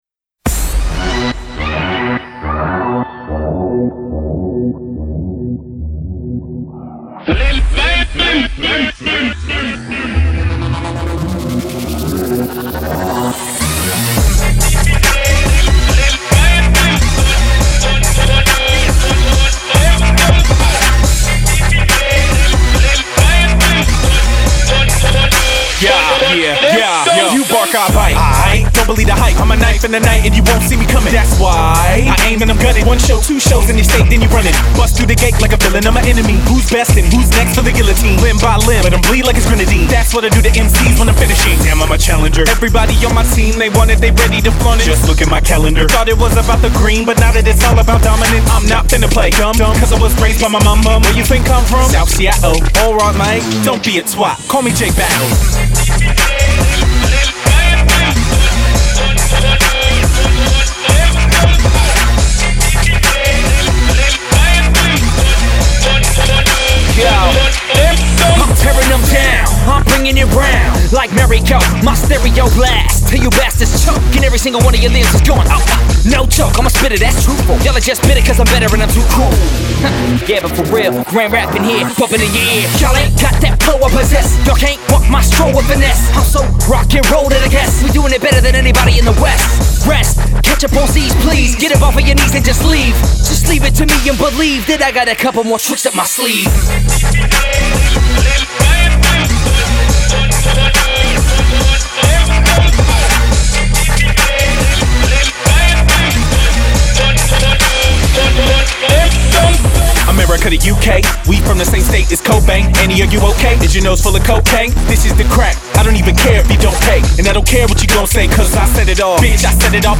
bouncy electronic kinetics and fast-rap